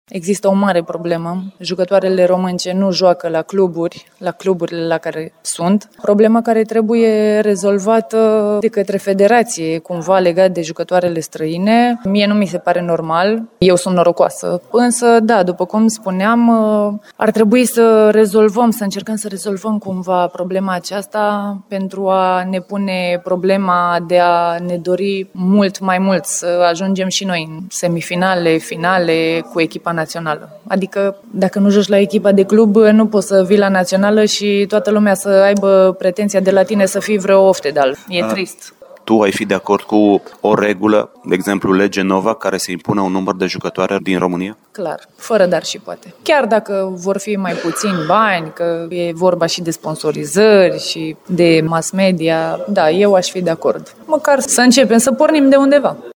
Într-un interviu pentru Radio Timișoara